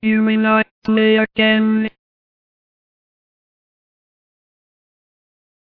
Video Game Sound FX Robot Voice, "You may not play again".
Video game sound effects. Robot voice says, "You may not play again."